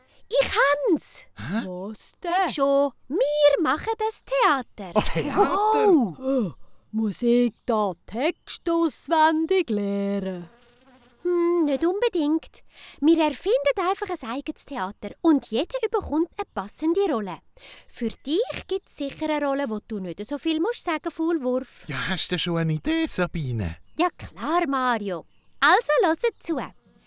★Abentüür uf de Wiese Folge 4★Dialekt Hörspiel★für Kreativ-Tonie